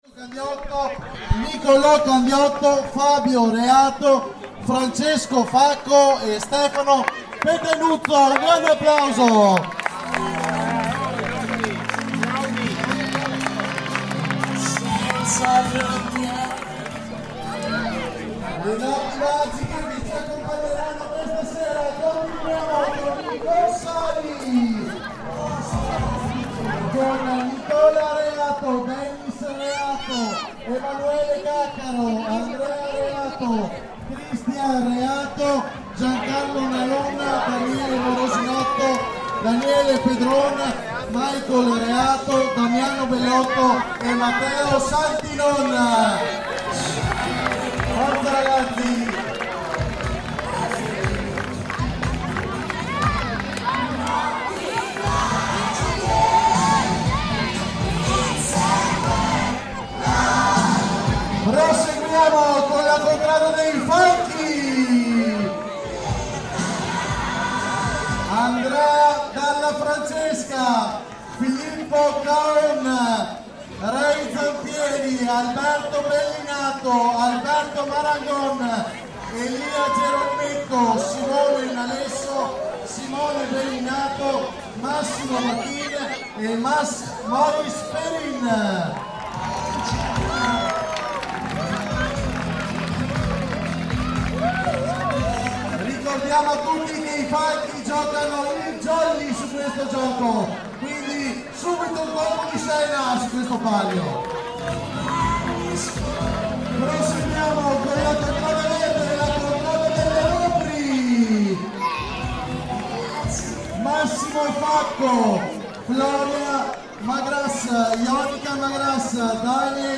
Annual "palio" games are common across Italy, in which the town divides itself up into different quarters, which then compete in a series of games over several nights - here, we listen to the first event, the tug of war, with several rounds between different teams all named af...
We can also hear the nightly "campana della sera" evening bells at 9.00pm midway through the recording. Recorded in Villa del Conte, Italy, June 2025 by Cities and Memory.